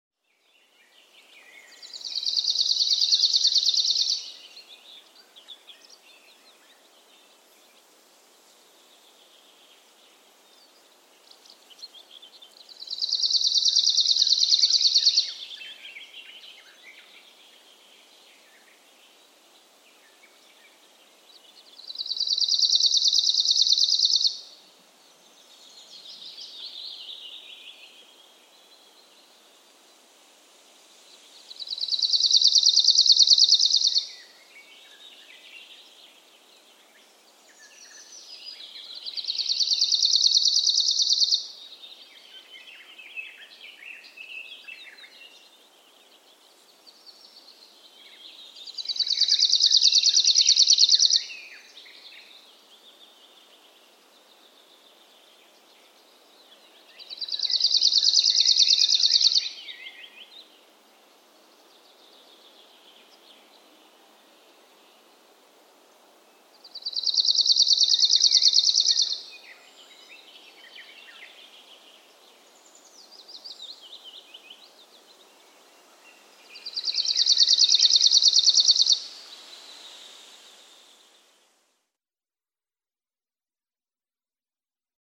Lapinuunilintu (Phylloscopus borealis)
Harvinaisen lapinuunilinnun laulun on vain harva päässyt kuulemaan. Se on tasainen helinä tai sirinä.